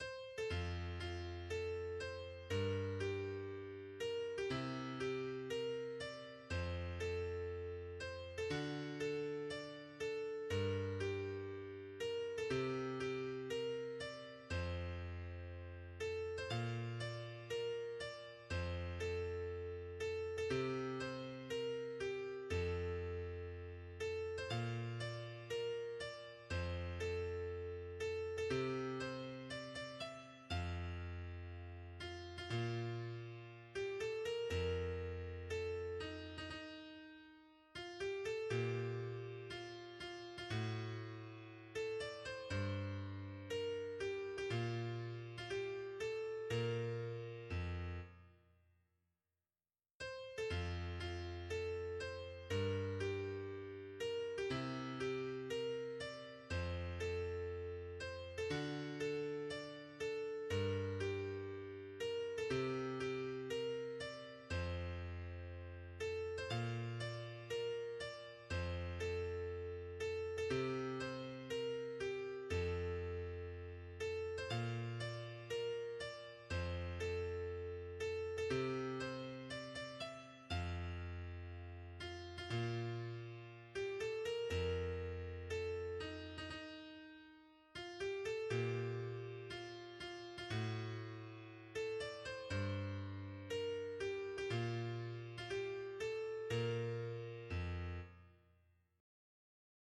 Áudio Karaoke -